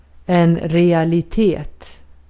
Ääntäminen
US : IPA : [ri.æl.ɪ.ti]